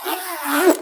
BackpackZipped.wav